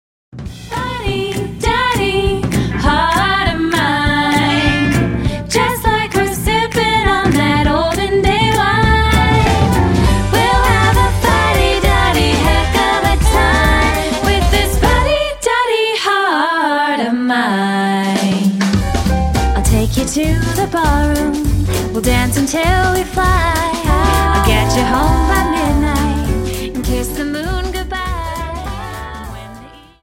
Dance: Quickstep Song